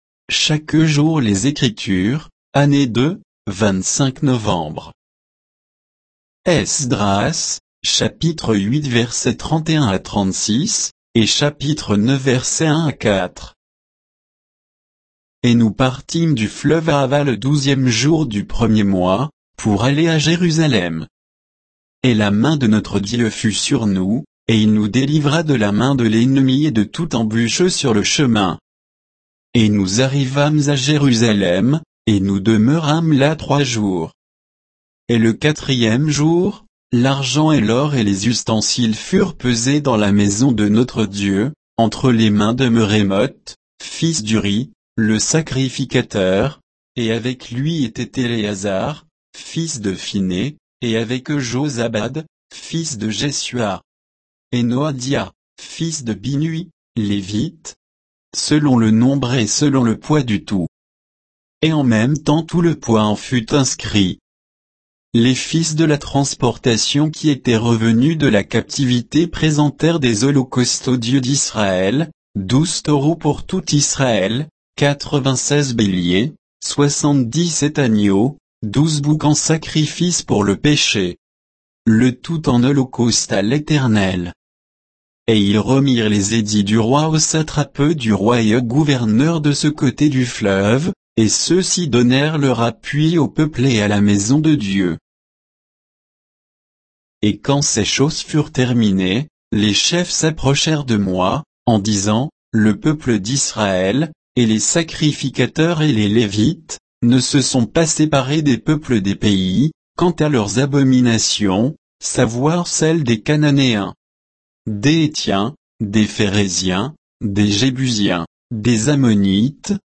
Méditation quoditienne de Chaque jour les Écritures sur Esdras 8